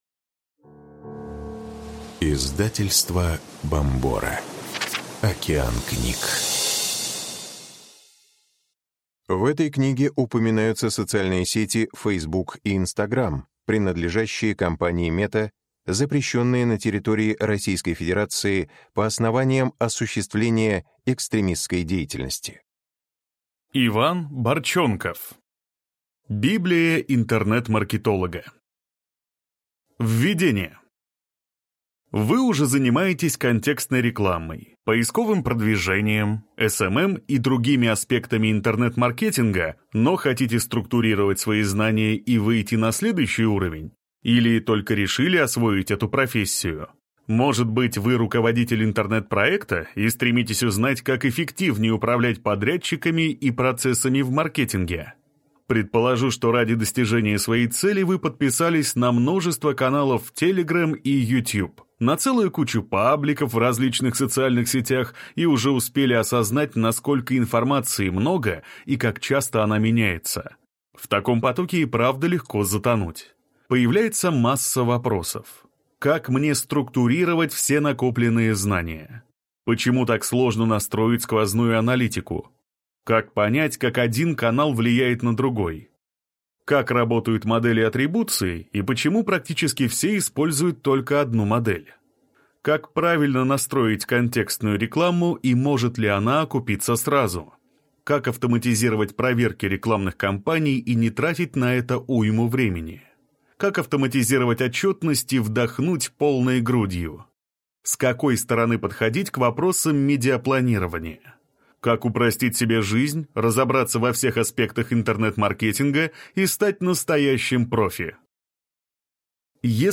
Аудиокнига Библия интернет-маркетолога | Библиотека аудиокниг
Прослушать и бесплатно скачать фрагмент аудиокниги